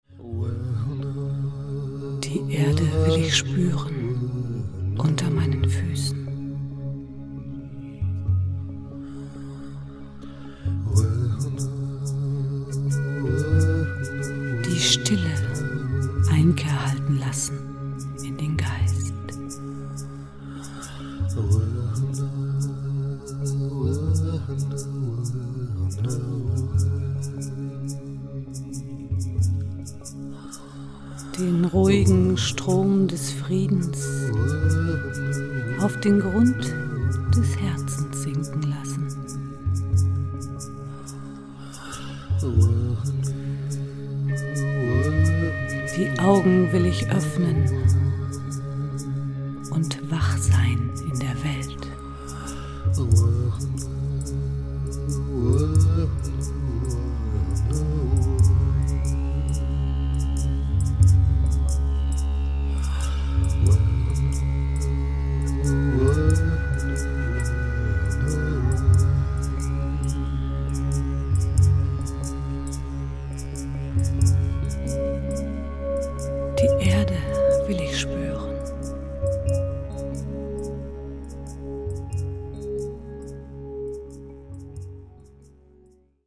meditativer Musik
Piano, Gesang und Flöten
warme, berührende Stimme